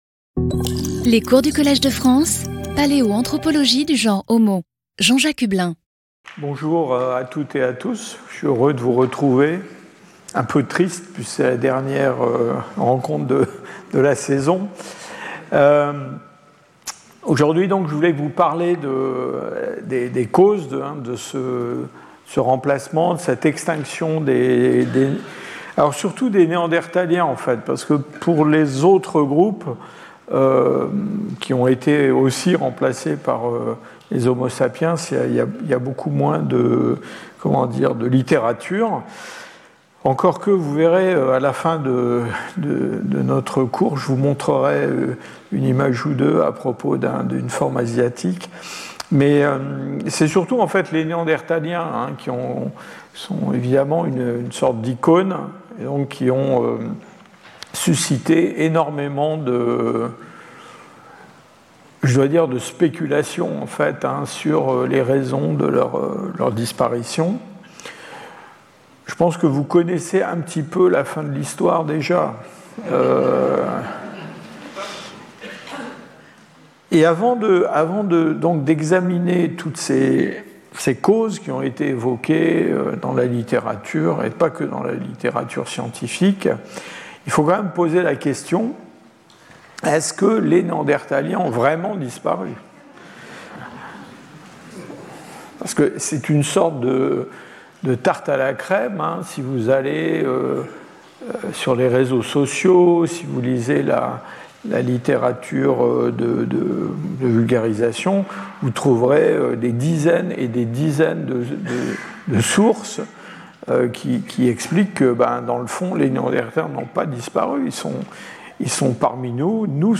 Intervenant(s) Jean-Jacques Hublin Professeur du Collège de France
Cours